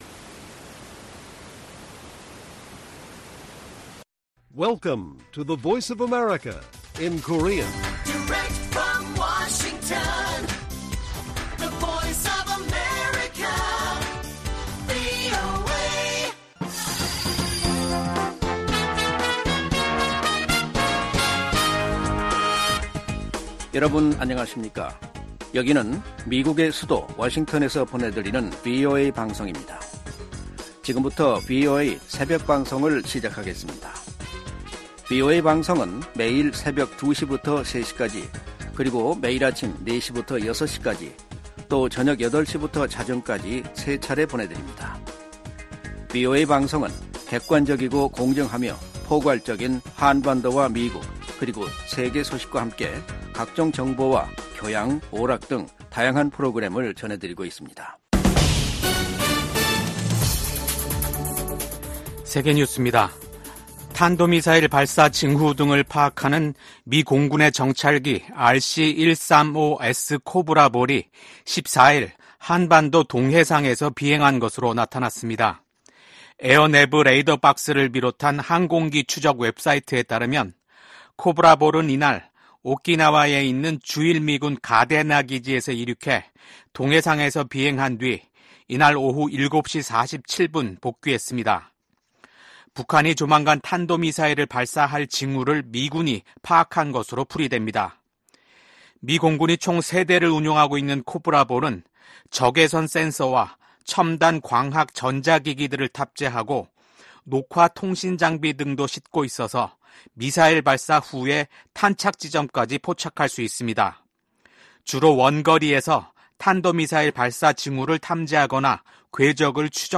VOA 한국어 '출발 뉴스 쇼', 2024년 6월 15일 방송입니다. 블라디미르 푸틴 러시아 대통령의 방북 임박설 속에 김일성 광장에 ‘무대’ 추정 대형 구조물이 등장했습니다. 미국의 전문가들은 푸틴 러시아 대통령의 방북이 동북아시아의 안보 지형을 바꿀 수도 있다고 진단했습니다. 미국 정부가 시행하는 대북 제재의 근거가 되는 ‘국가비상사태’가 또다시 1년 연장됐습니다.